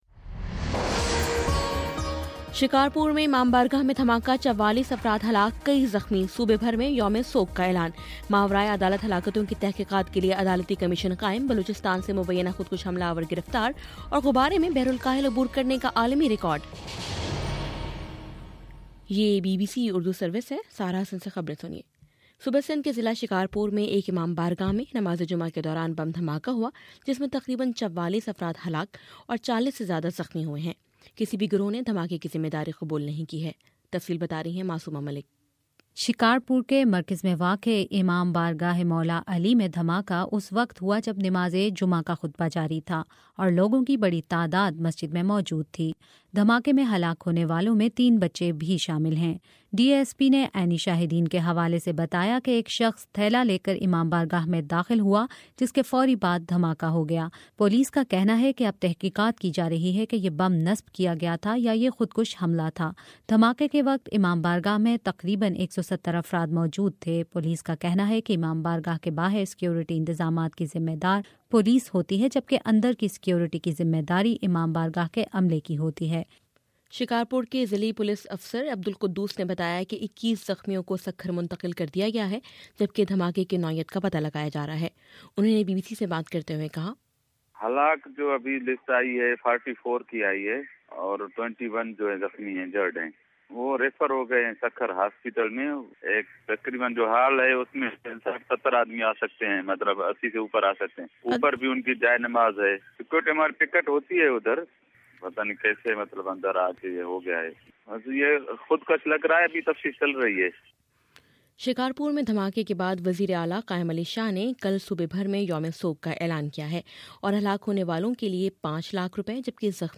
جنوری 30: شام سات بجے کا نیوز بُلیٹن